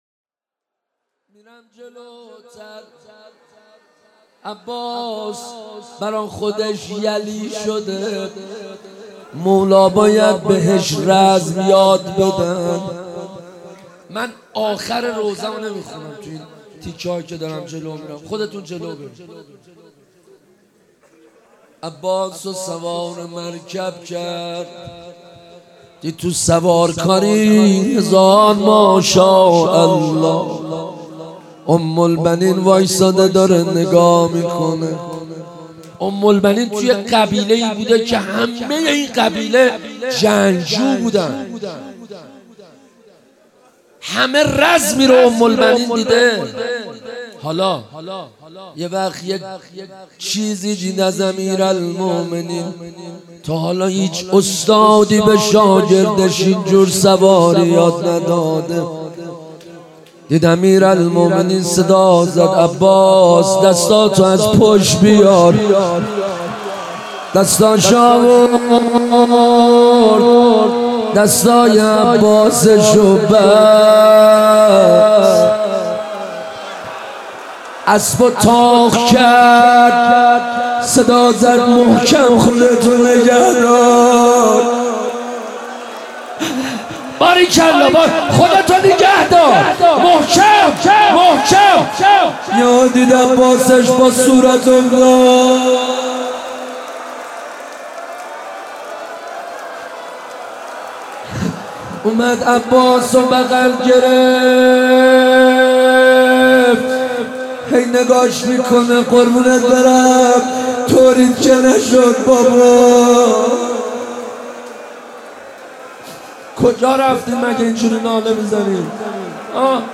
مناسبت : شب هشتم محرم
قالب : روضه